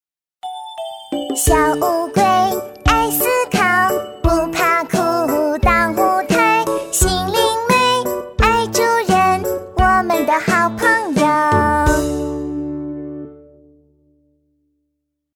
• 女S155 国语 女声 歌曲翻唱唱歌-女童-活泼、可爱 亲切甜美|素人